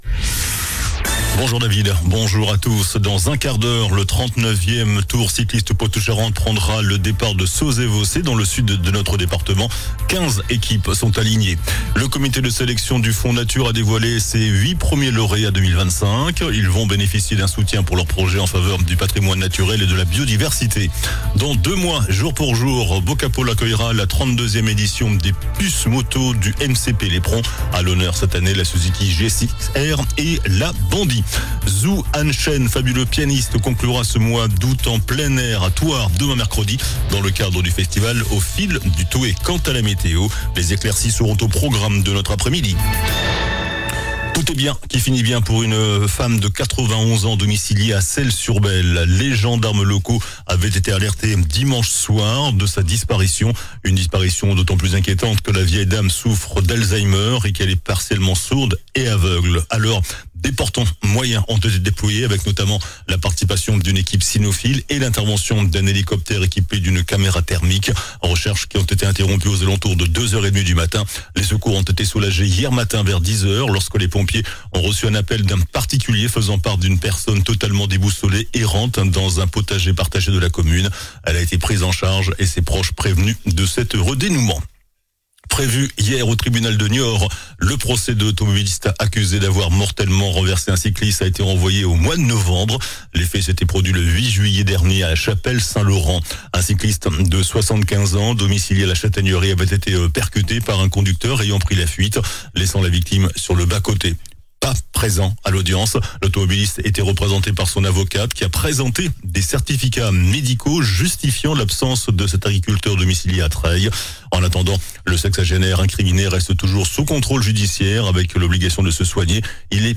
JOURNAL DU MARDI 26 AOÛT ( MIDI )